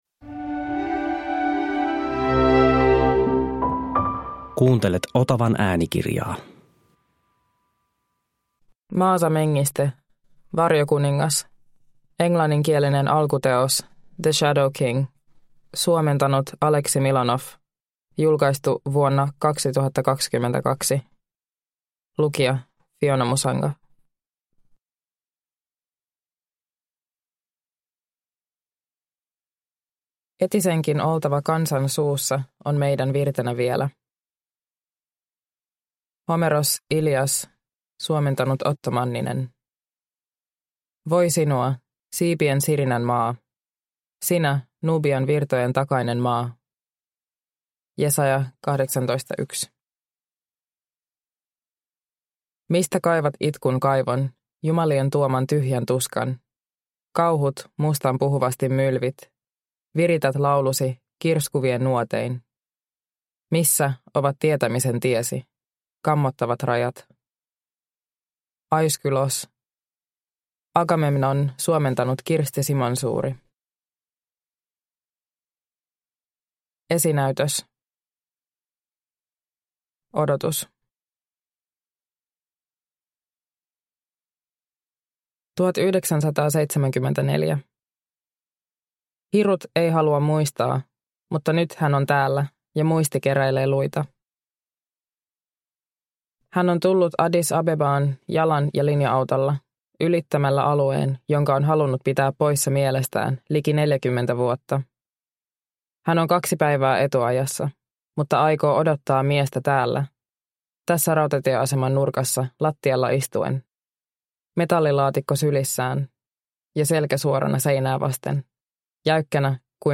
Varjokuningas – Ljudbok – Laddas ner